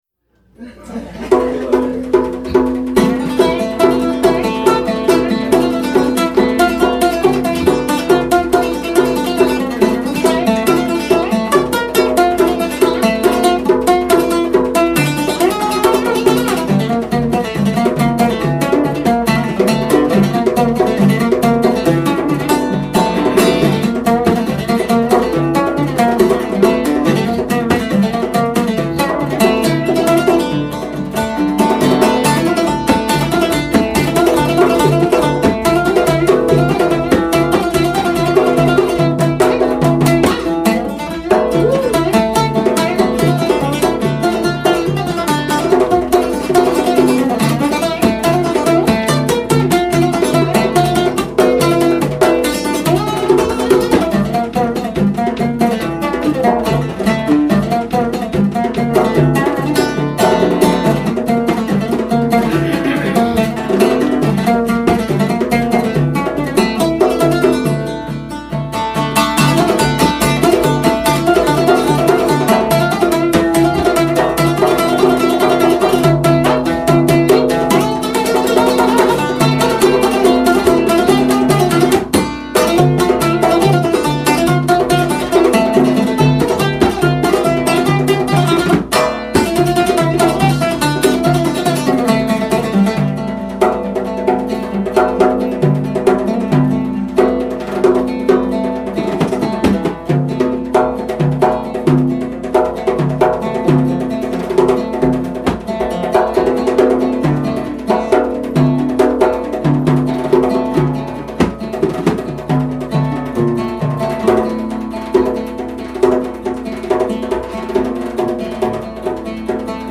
Rockport MA